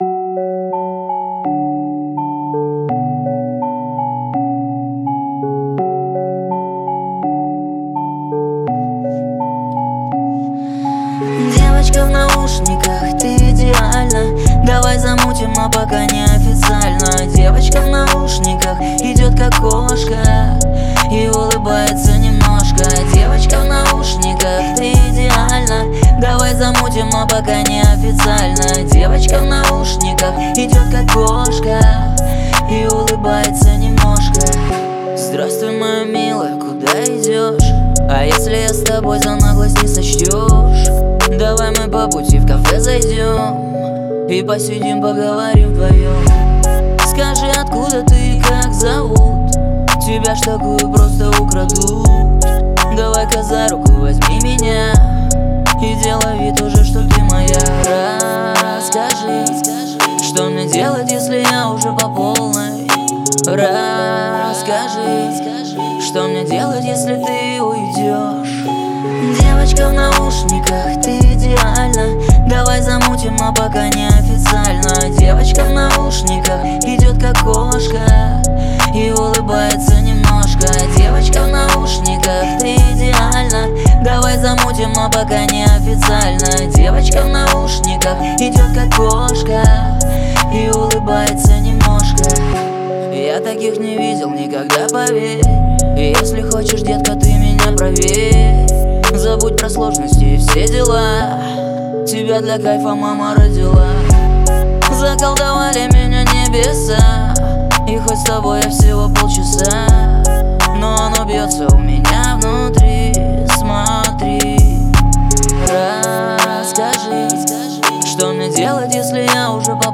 в жанре поп-рок